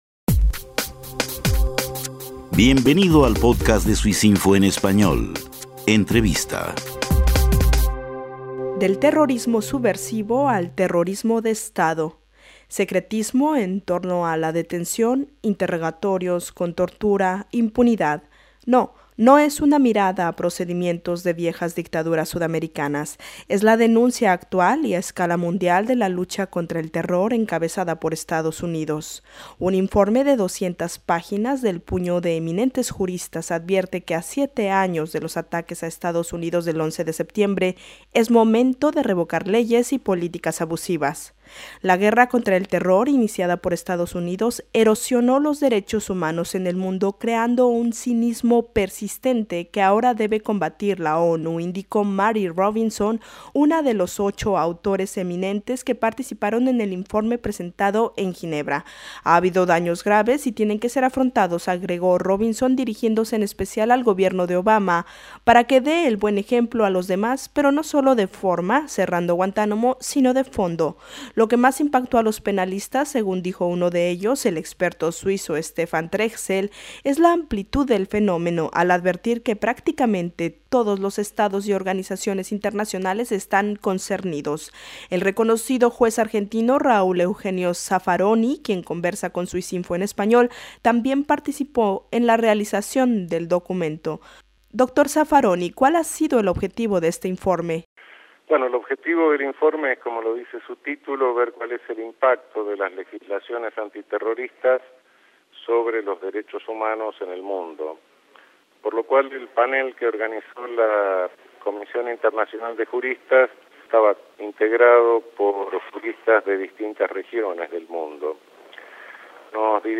Archivo MP3 El derecho penal debe ser la primera herramienta de la lucha contra aquellos que cometen actos de terror, reitera el Panel de ocho eminentes juristas reunido por la Comisión Internacional de Juristas. El juez Raúl Eugenio Zaffaroni, miembro del grupo de prestigiosos jueces y abogados, pide que no se cedan los valores para la defensa de los derechos humanos a nombre de una supuesta lucha terrorista. Entrevista